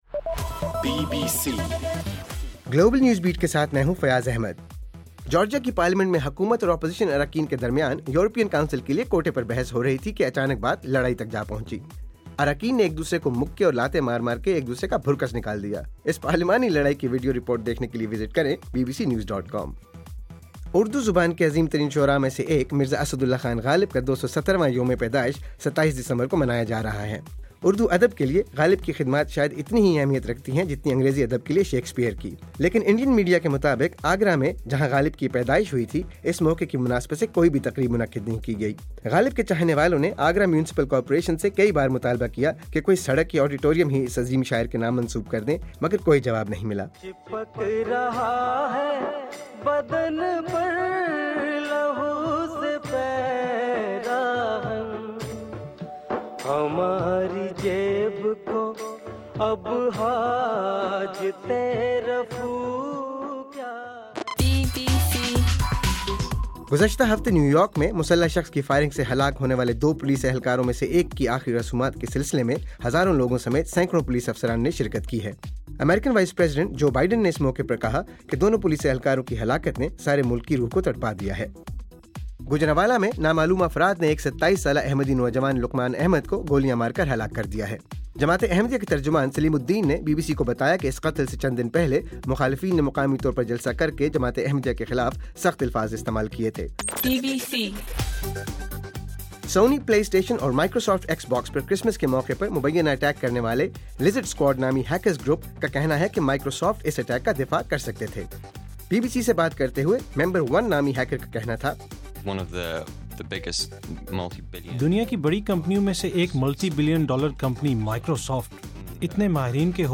دسمبر 28: صبح 1 بجے کا گلوبل نیوز بیٹ بُلیٹن